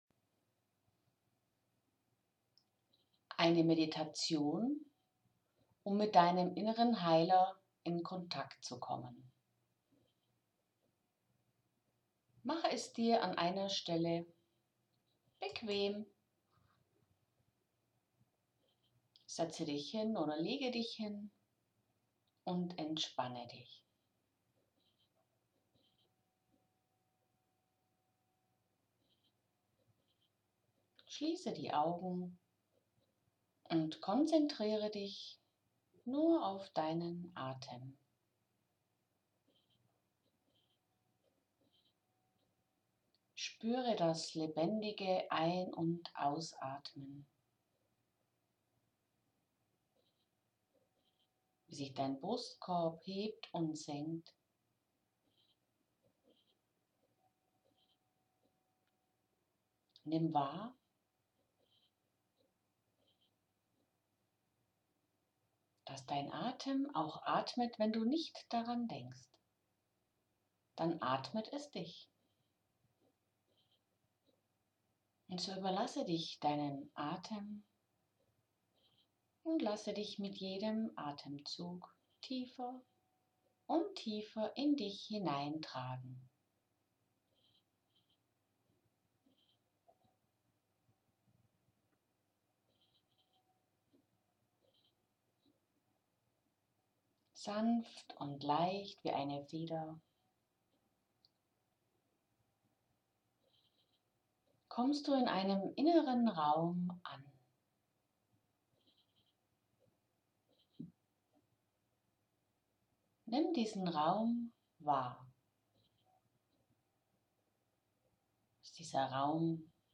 Folgende kleine Meditation kann Dir helfen, den Kontakt zu deinem inneren Heiler herzustellen: